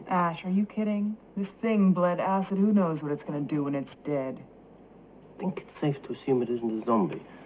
She screams in horror and throws it to the floor.